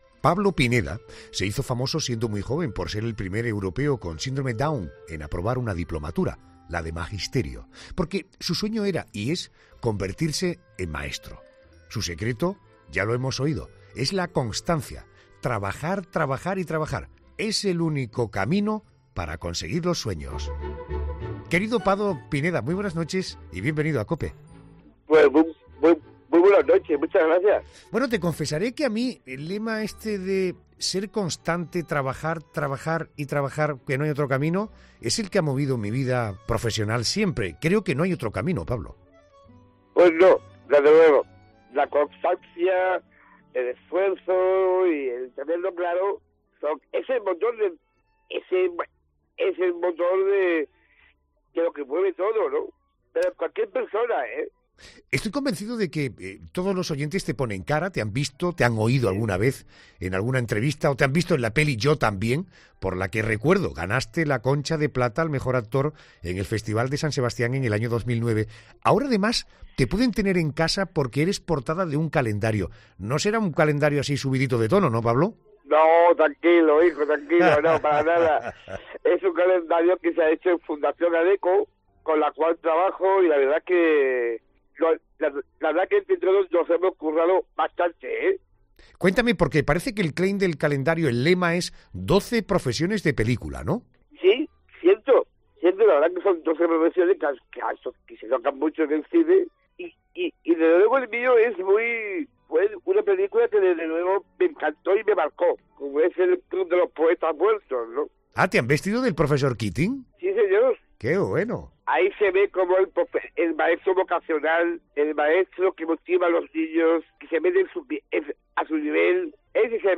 Eso ha cambiado y ahora en el mundo Down me aceptan un poco más y en el mundo ‘normal’ me aceptan bastante más’, explica de forma sincera y relajada.